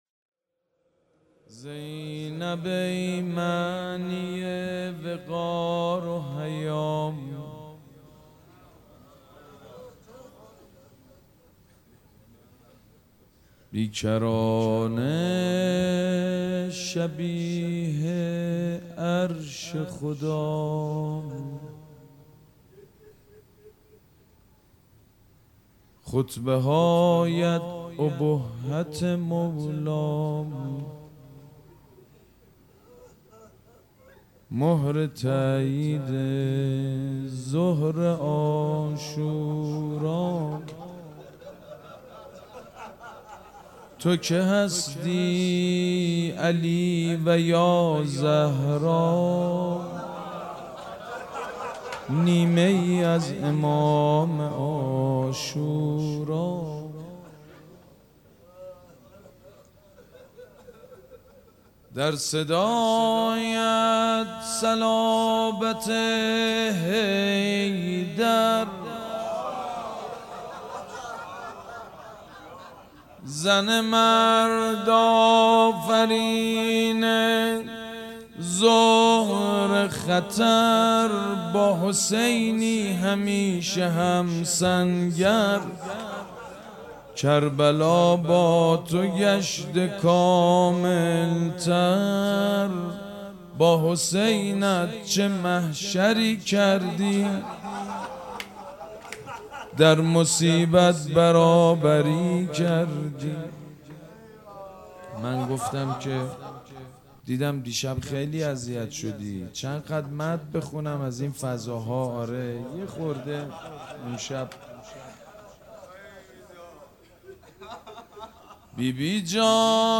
شب چهارم در حسینیه ریحانه الحسین
مدح